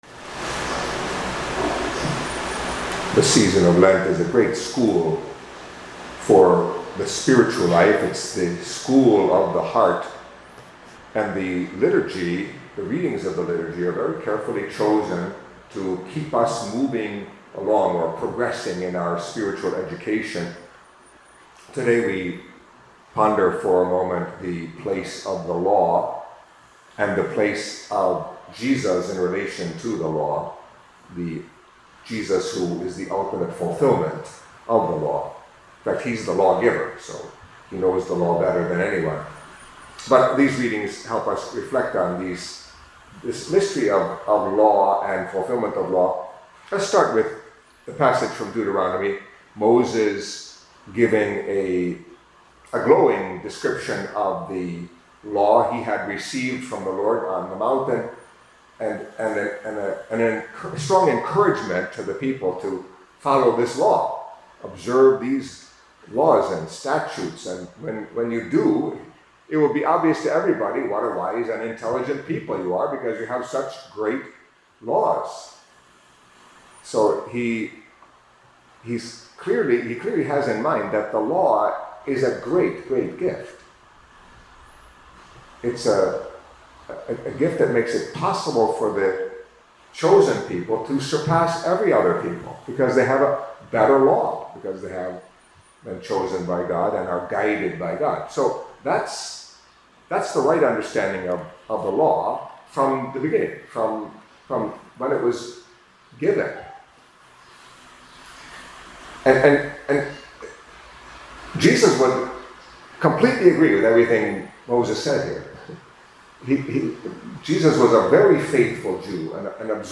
Catholic Mass homily for Wednesday of the Third Week of Lent